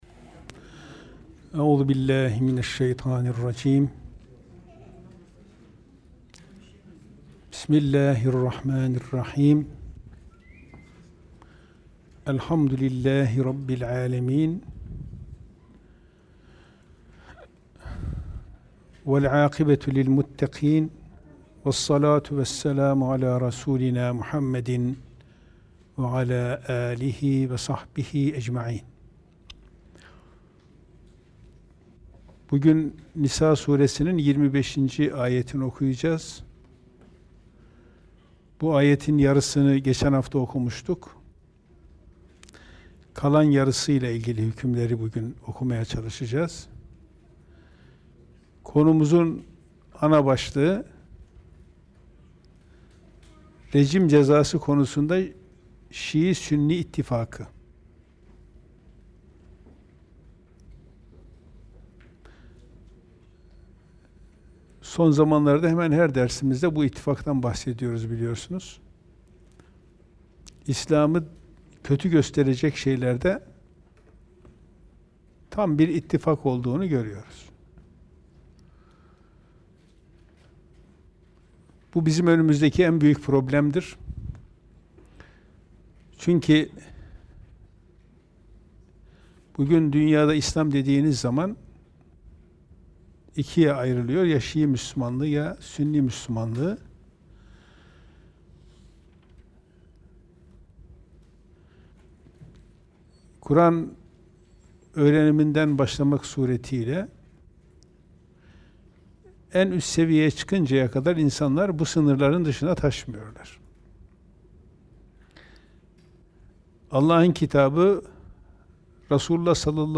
2.496 görüntülenme Kur'an Sohbetleri Etiketleri